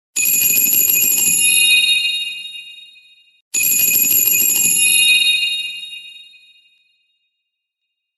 eletronic-ring_24951.mp3